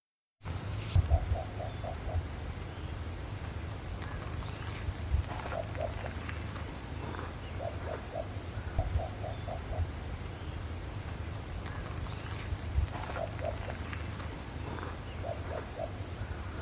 Rufous-legged Owl (Strix rufipes)
Location or protected area: El Bolsón
Detailed location: Cascada Escondida.
Condition: Wild
Certainty: Recorded vocal
lechuza-bataraz-austral.mp3